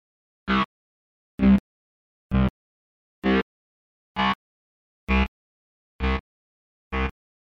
Tag: 130 bpm Electro Loops Bass Loops 1.24 MB wav Key : Unknown